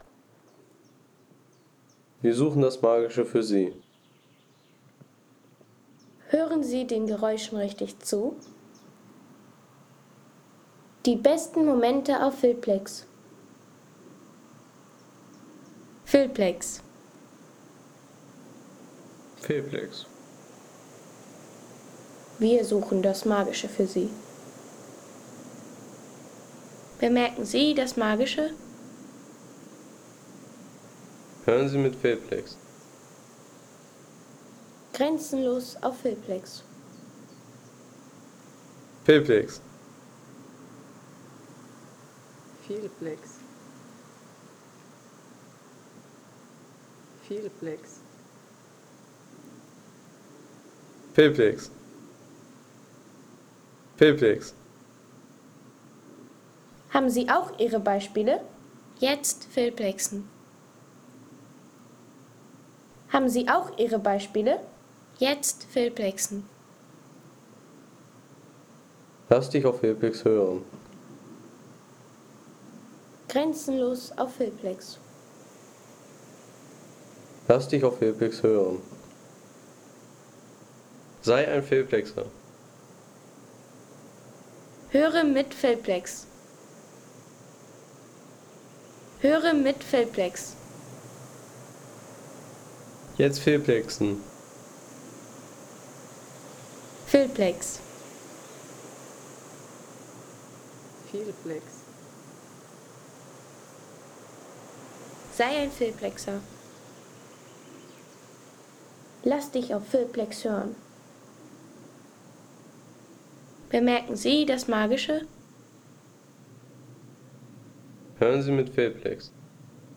Authentische Bergatmosphäre vom Carolafelsen in der Sächsischen Schweiz mit sanftem Wind, Vogelstimmen und ruhiger Abendstimmung.
Ein ruhiger Bergsound aus der Sächsischen Schweiz mit sanftem Wind, Vogelstimmen und stiller Abendstimmung für Filme, Reisevideos, Dokus und Sound-Postkarten.